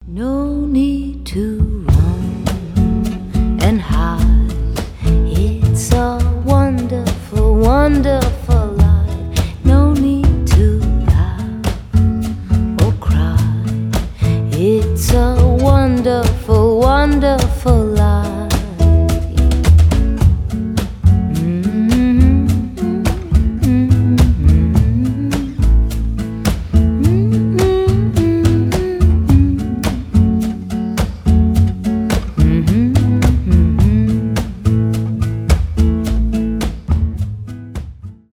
поп , гитара , акустика , приятные